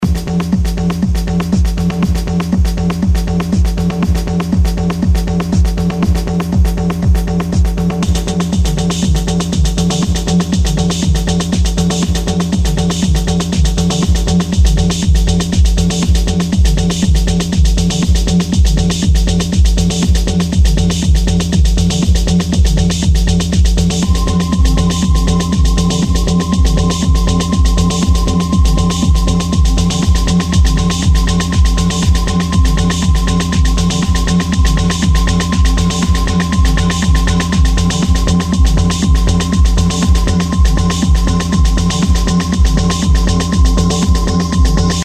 EBM
120BPM